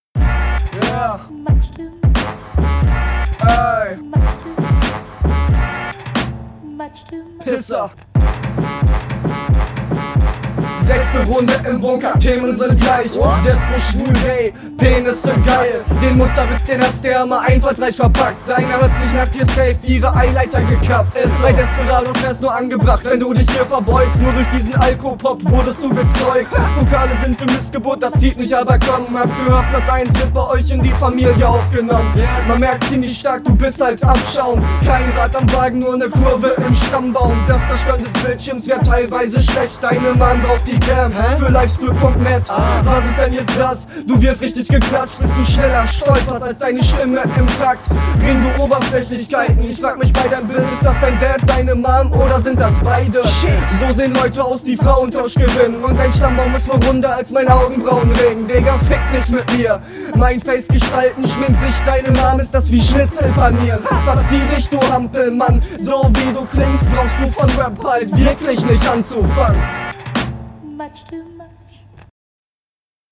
Ich mag deine Stimme, aber die Mische ist echt verbesserungswürdig.
Audio schon mal wesentlich schlechter.